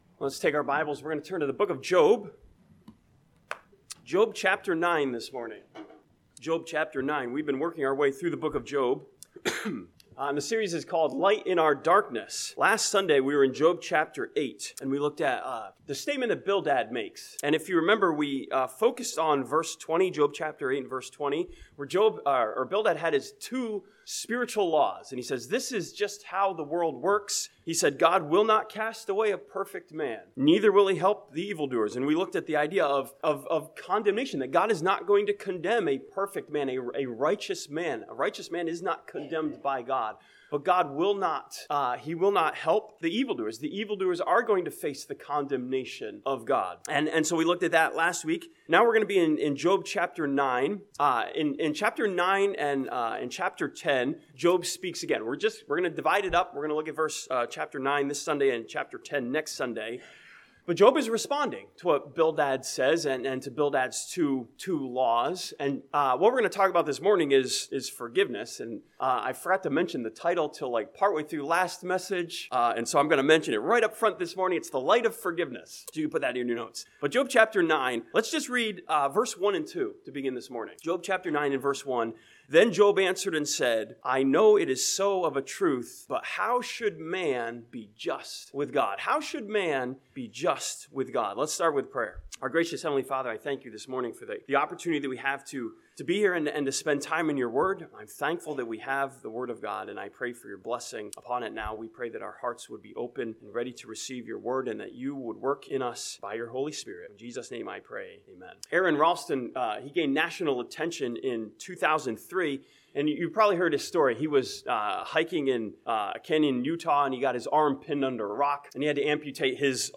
This sermon from Job chapter 9 challenges us to find the light of God's forgiveness through Christ in the darkness of our condemnation.